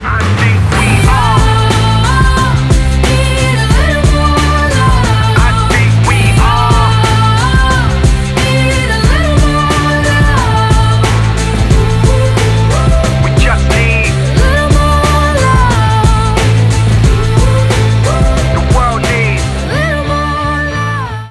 Приятный женский голос звучит в припеве этого рэп-трека